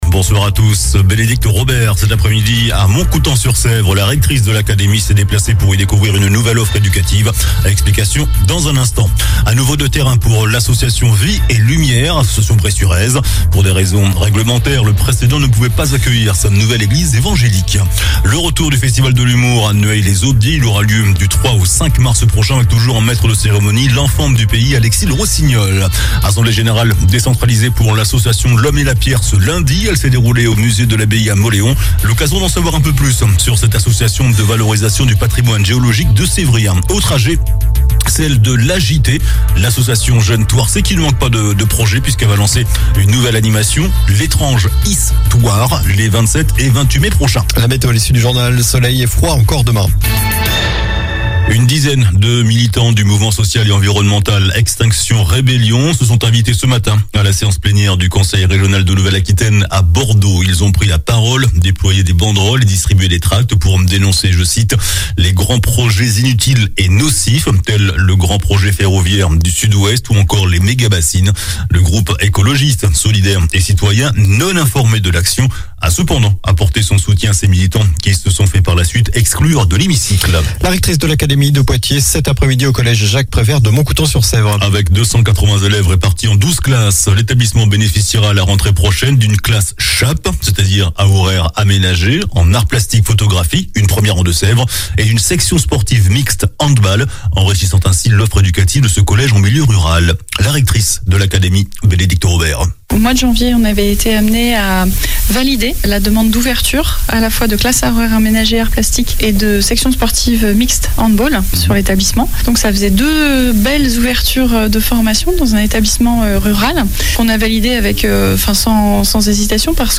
JOURNAL DU LUNDI 27 FEVRIER ( SOIR )